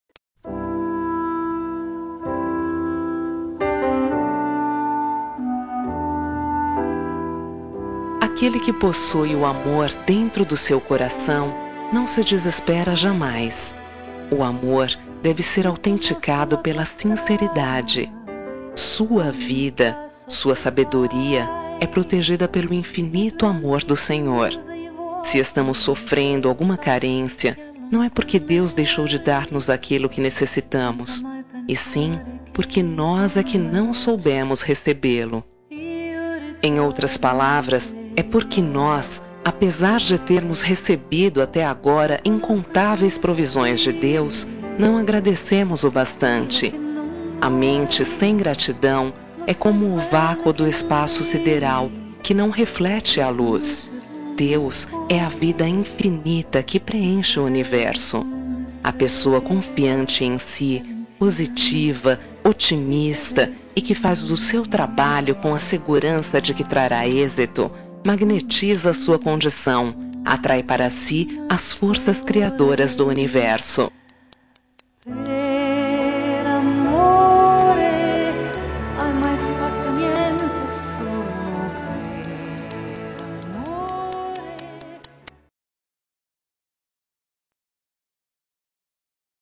Telemensagem de Otimismo – Voz Feminina – Cód: 176